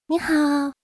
挥手.wav
人声采集素材/人物休闲/挥手.wav